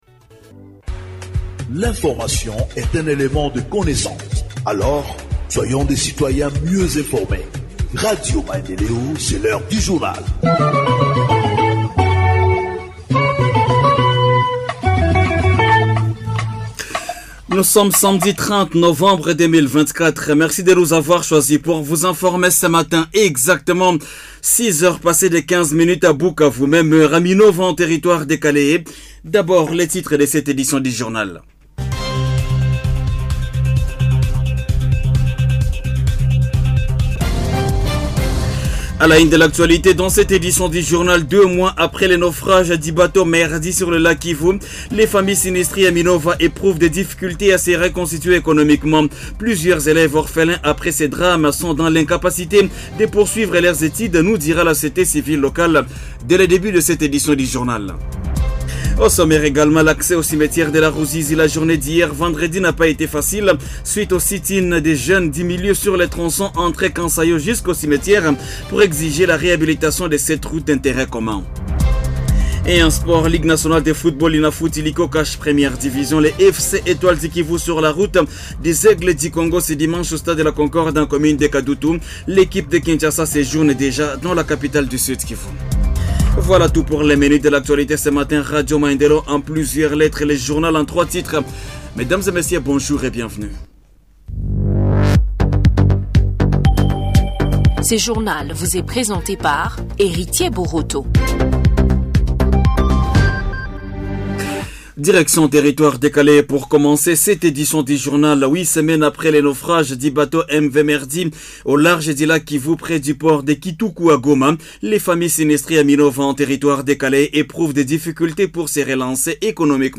Journal en Français du 30 novembre 2024 – Radio Maendeleo